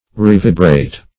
Re*vi"brate